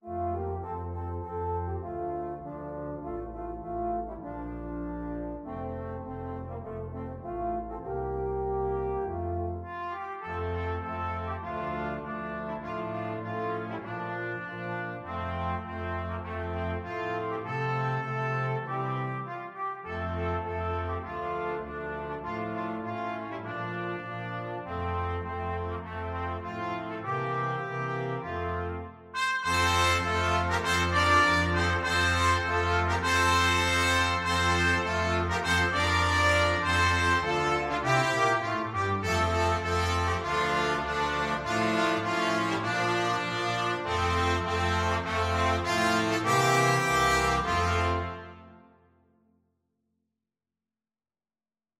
Trumpet 1Trumpet 2French HornTromboneTuba
Moderato
4/4 (View more 4/4 Music)
Brass Quintet  (View more Easy Brass Quintet Music)
Traditional (View more Traditional Brass Quintet Music)